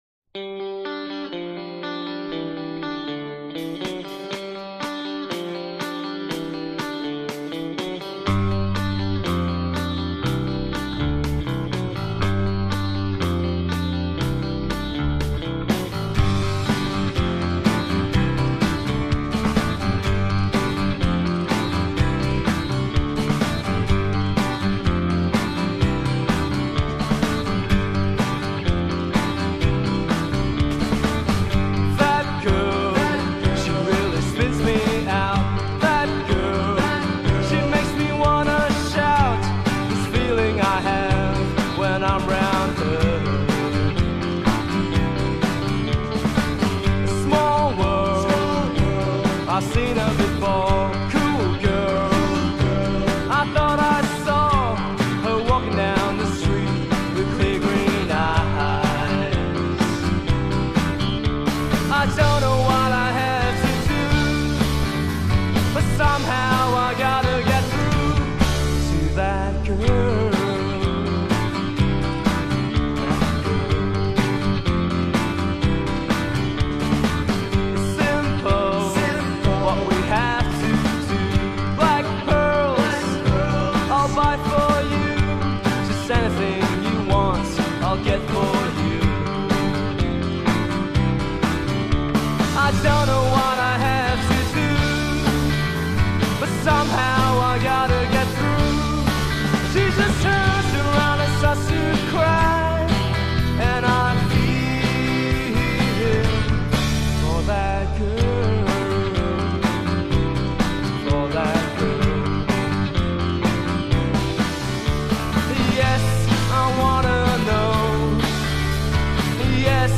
bass,  vocals
drums, percussion
guitar, vocals
violin, vocals